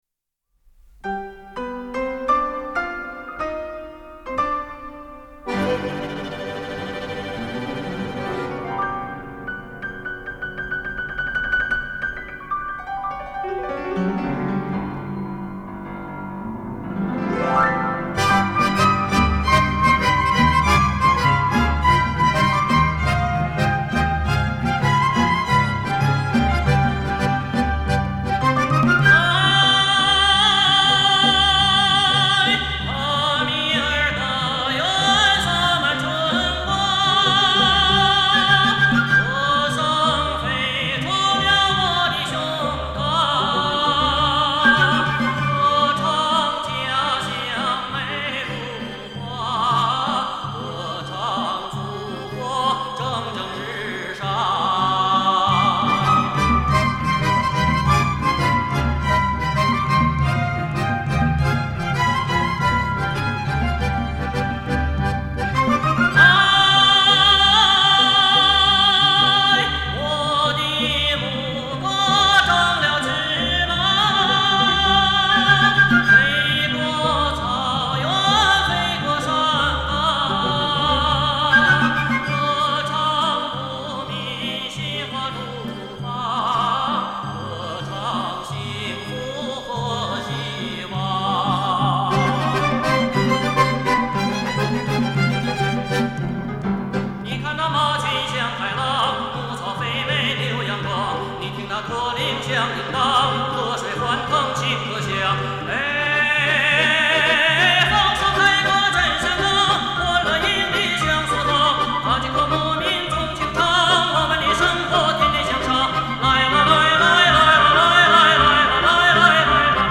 专辑风格：中国民歌